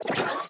Cybernetic Technology Affirmation 6.wav